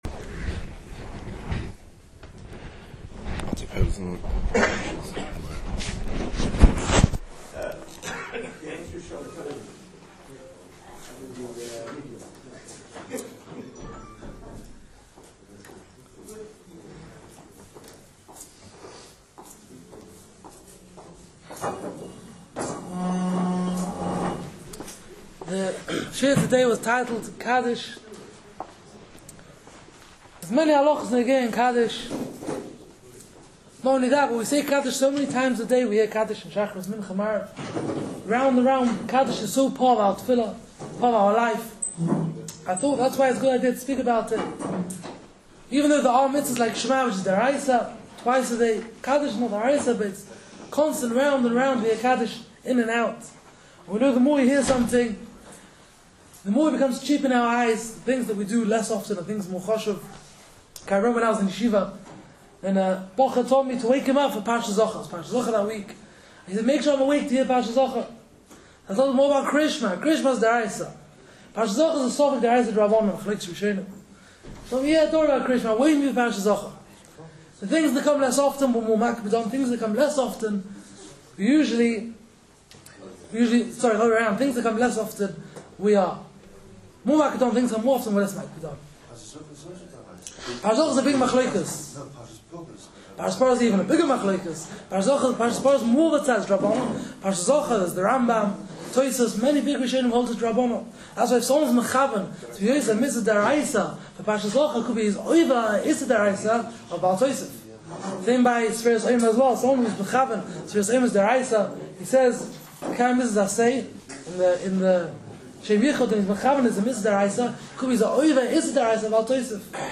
Start Your Day The TorahWay Manchester provides daily shiurim on a wide range of topics.